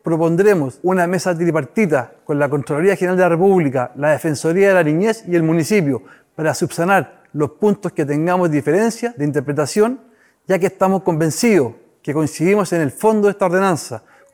El alcalde de Zapallar, Gustavo Alessandri, confirmó que propondrán una mesa tripartita con la Contraloría General de la República y la Defensoría de la Niñez para subsanar las diferencias de esta ordenanza.